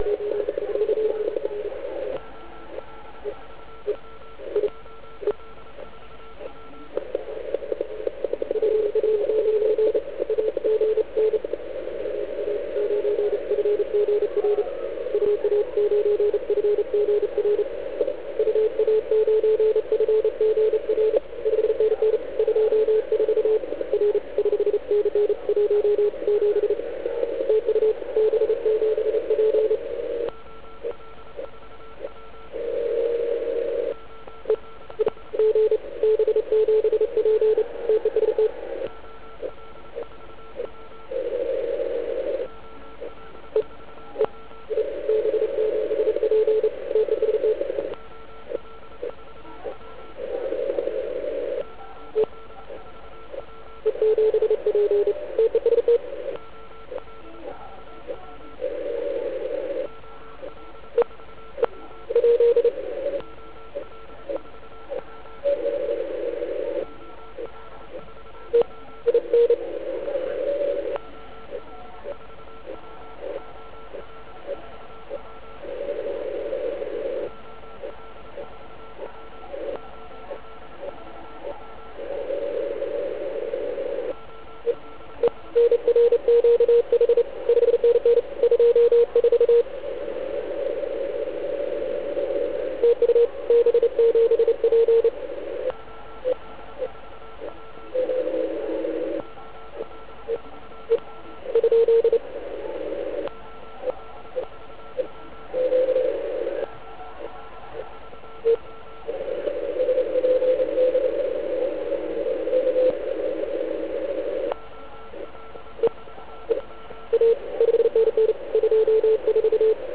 B7P produkuje silný signál.
B7P marné volání (*.wav 914KB)